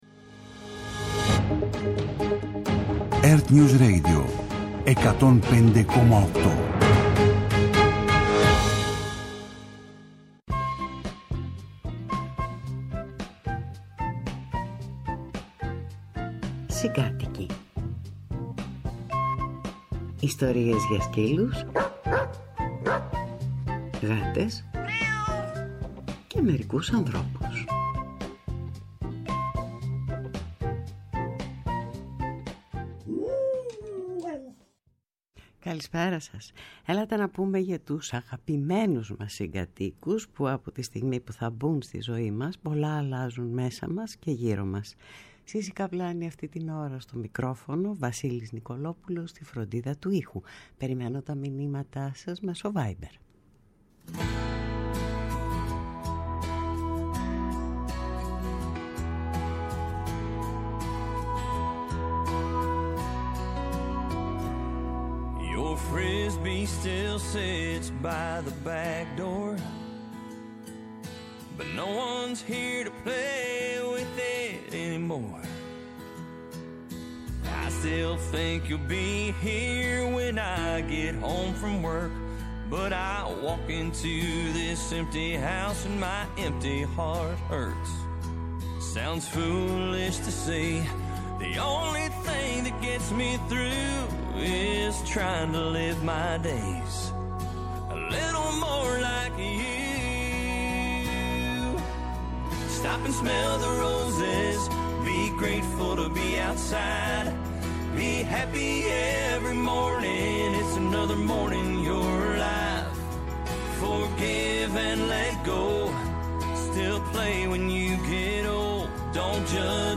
Μια ραδιοφωνική εκπομπή που όλοι οι καλοί χωράνε.